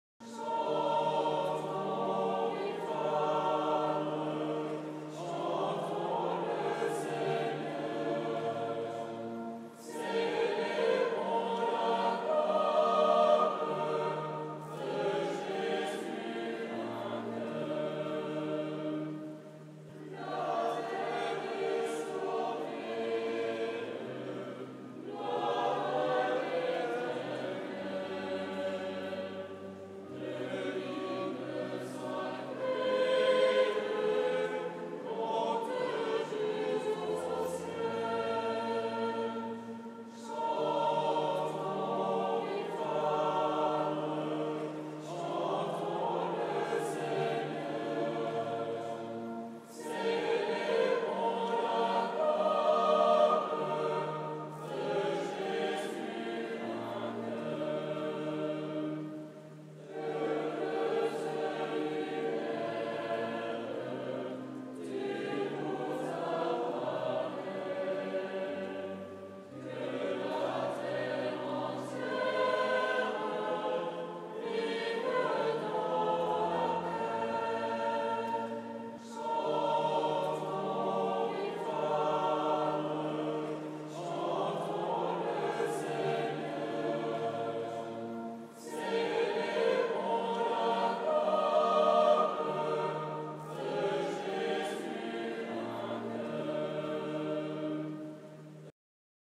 Eglise Saint-François-de-Paule Fréjus - Samedi Saint - Vigile Pascale et messe de la Résurrection